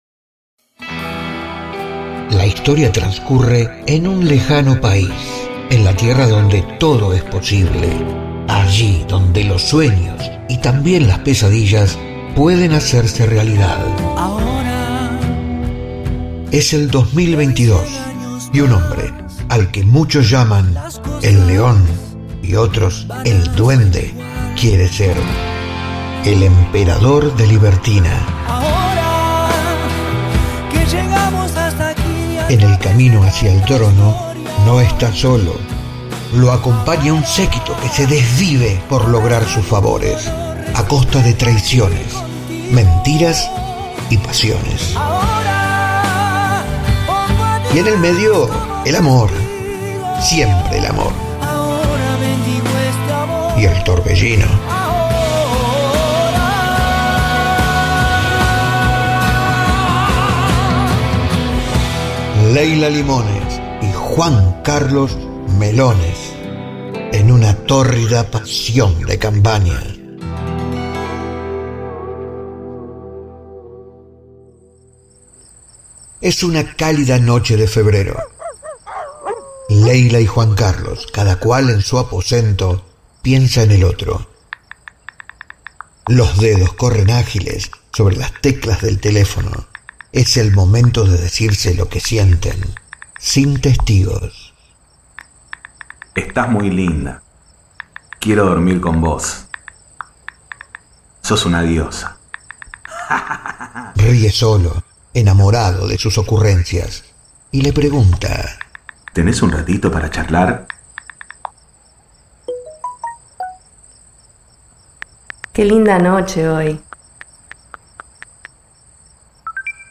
Así arranca el primer capítulo del radio teatro que nada tiene para envidiarle a la maravillosa pluma de Alberto Migré.